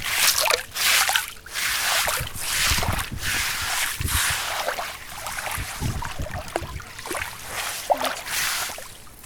젖은모래쓸어내기.ogg